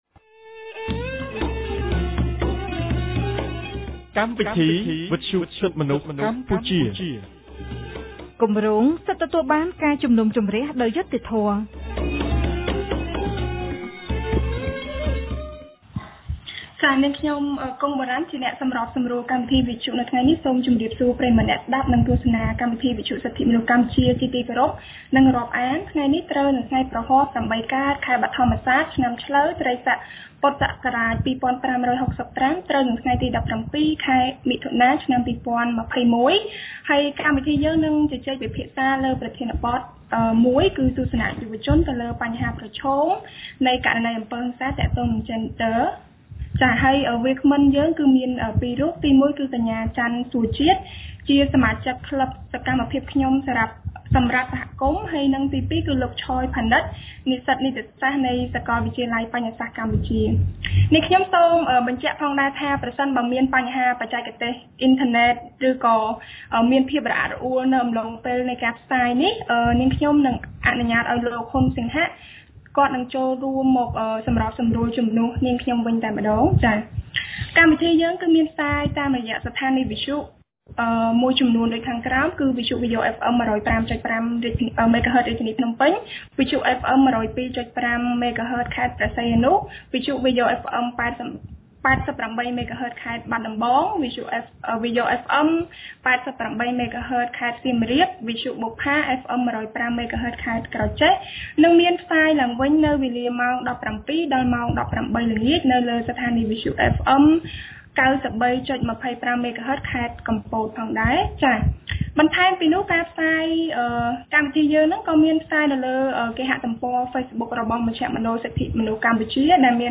បានរៀបចំកម្មវិធីវិទ្យុក្រោមប្រធានបទស្តីពី“ទស្សនៈយុវជនលើបញ្ហាប្រឈមនៃករណីអំពើហិង្សាទាក់ទងនឹងយេនឌ័រ”